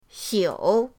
xiu3.mp3